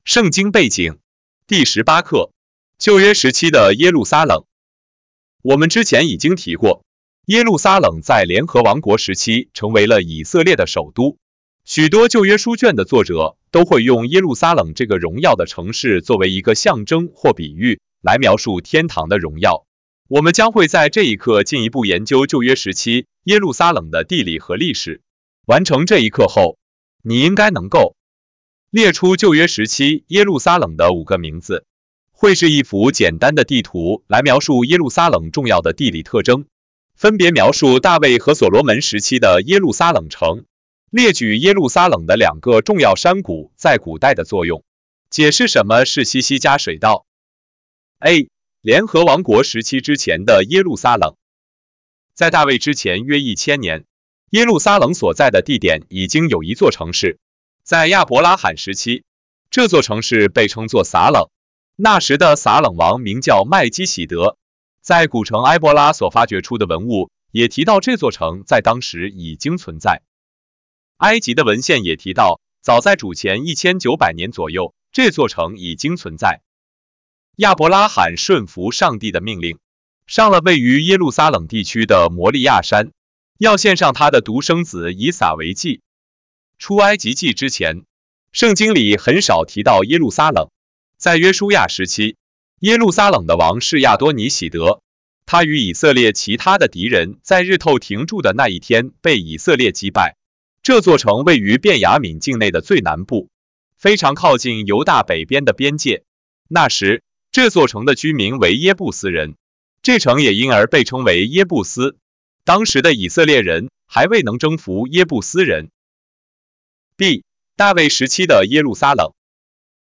18-第十八课-旧约时期的耶路撒冷.mp3